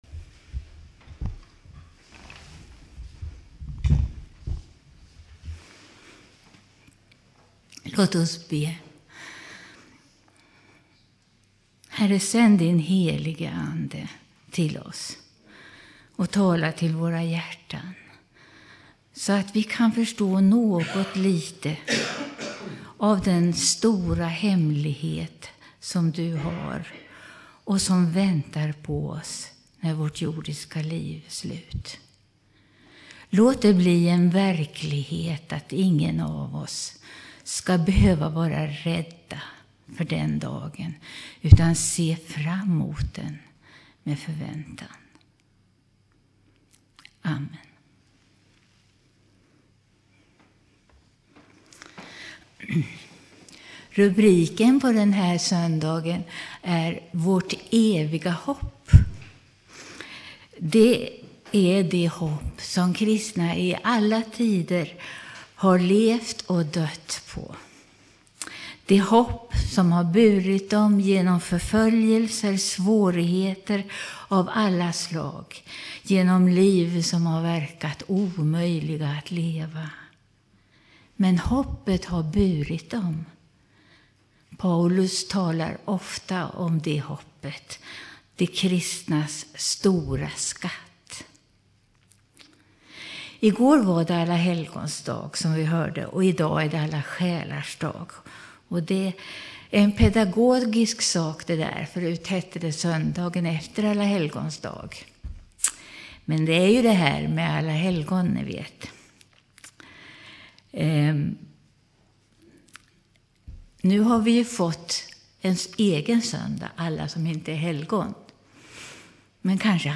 2007-11-04 Predikan av